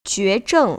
[juézhèng] 쥐에정